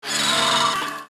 Kamacuras_godzilla_anime_roar.mp3